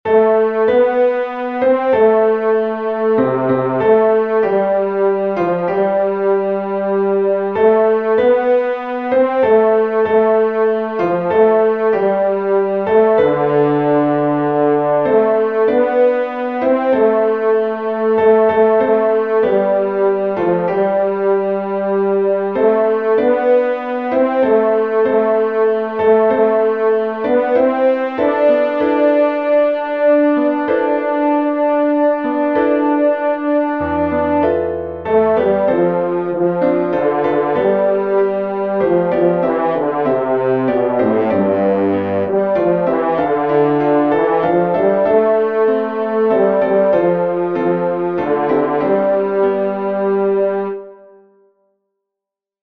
BASS
dream_isaiah_saw_two-bass.mp3